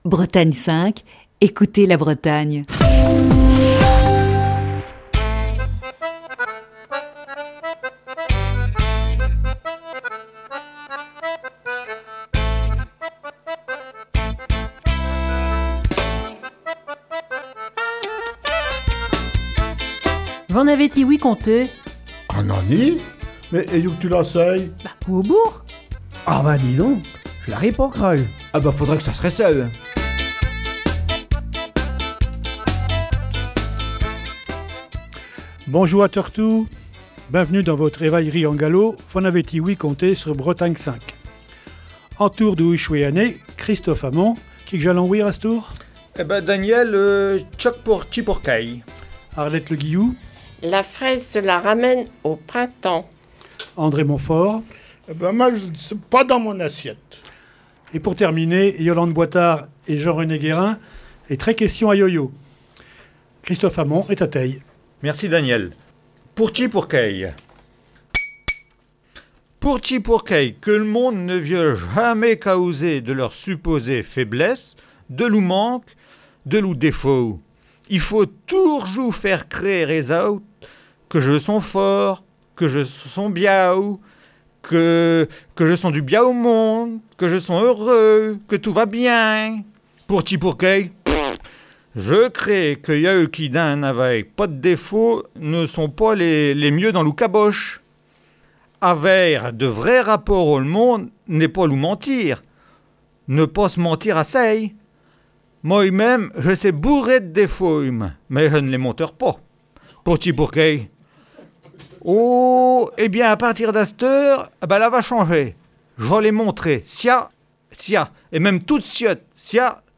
Il est question des avalanches, parfois verbaux, avec toute la troupe de V'en avez ti-ouï conté, réunie ce matin autour du micro de Bretagne 5.